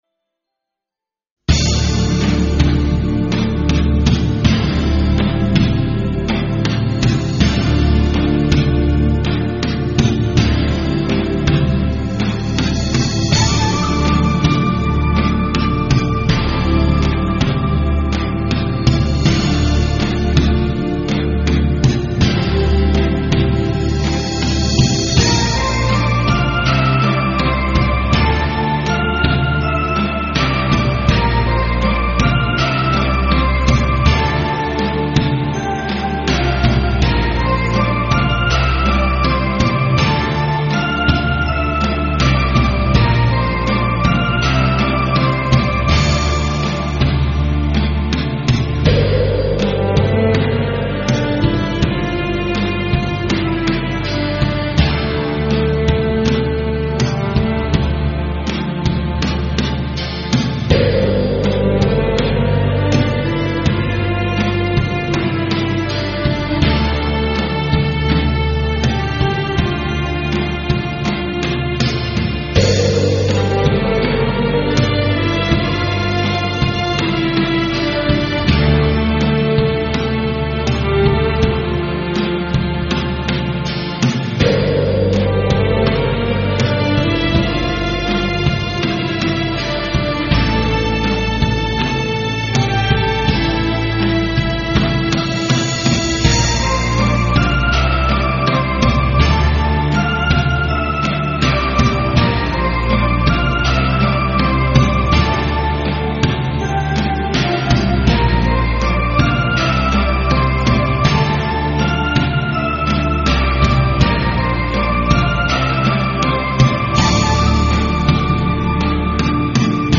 同樣以空靈而超俗的樂風， 征服了全世界的人心。